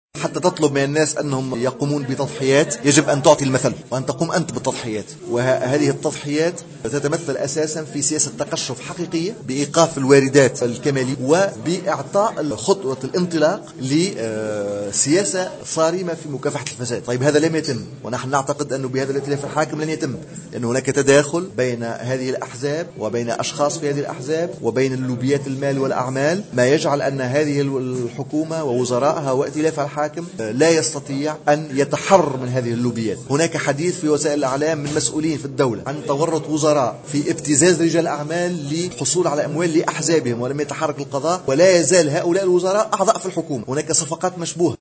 وأضاف منصر خلال ندوة صحفية اليوم أن عددا من المسؤولين في الدولة كشفوا في تصريحات إعلامية عن تورط وزراء في ابتزاز رجال أعمال مقابل الحصول على أموال لأحزابهم، لكن القضاء لم يتحرّك.